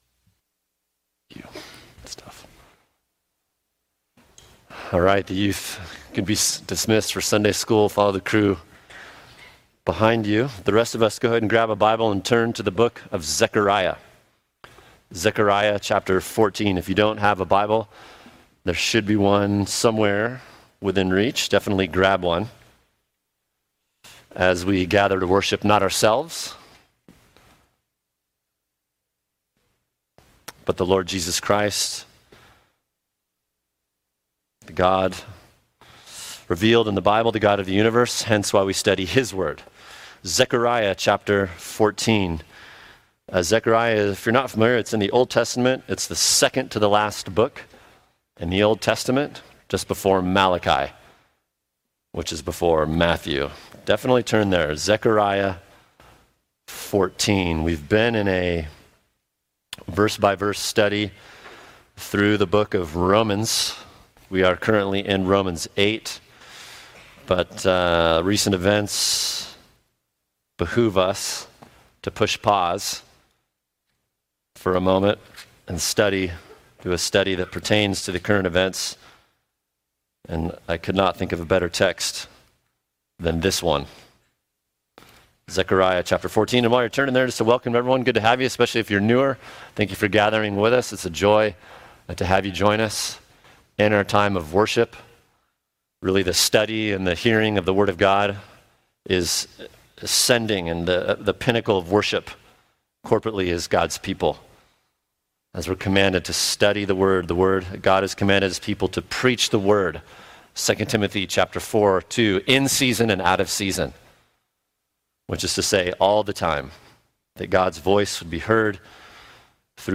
[sermon] Zechariah 14 What Is Going To Happen In The Middle East?